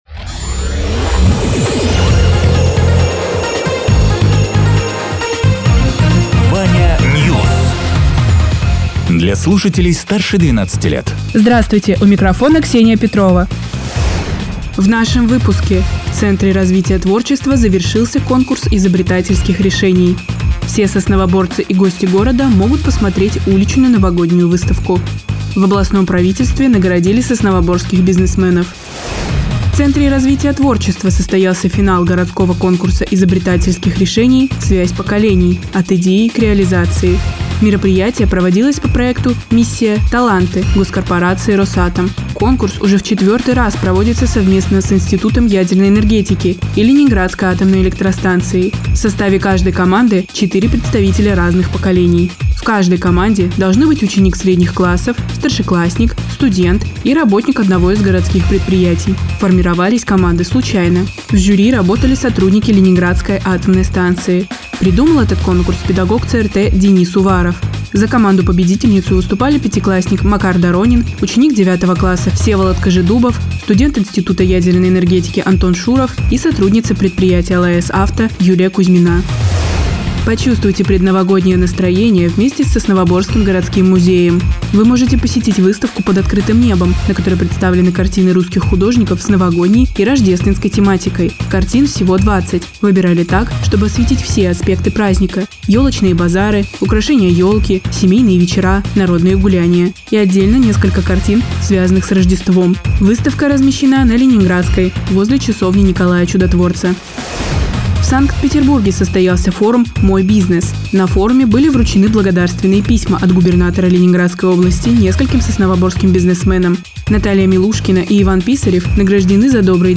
Радио ТЕРА 27.12.2024_10.00_Новости_Соснового_Бора